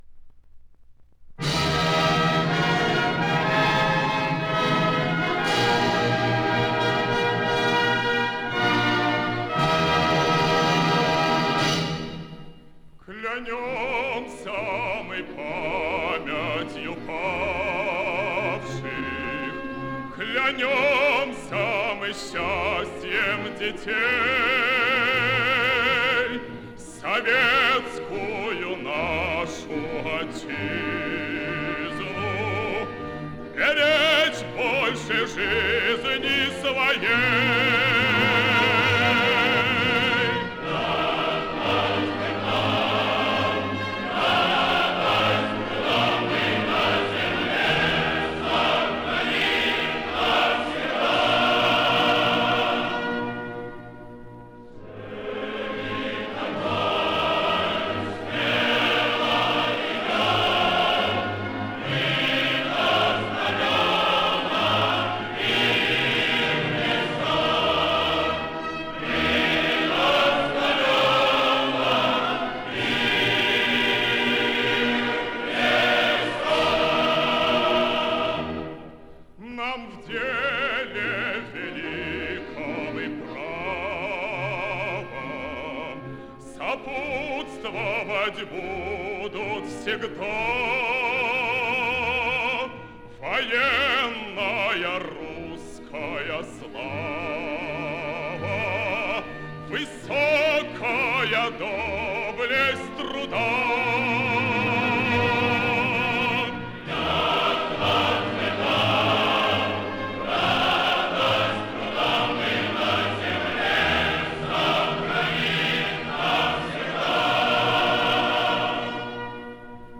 Повышение качества. С иностранного диска.